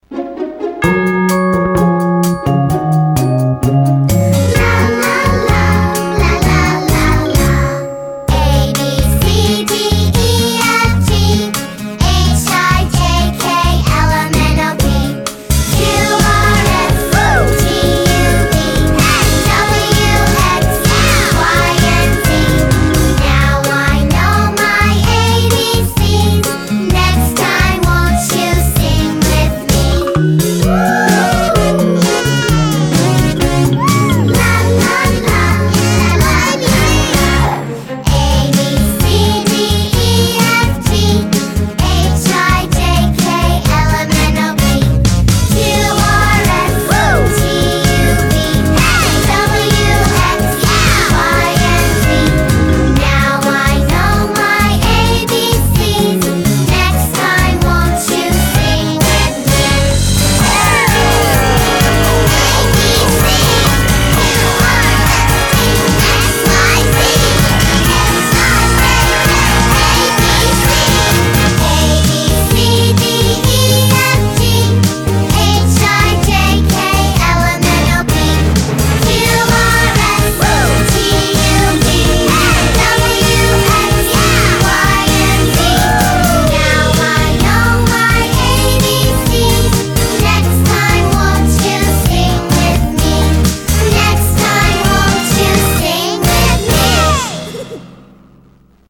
Children's Song
BPM129-129
Audio QualityPerfect (High Quality)